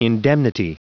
Prononciation du mot indemnity en anglais (fichier audio)
Prononciation du mot : indemnity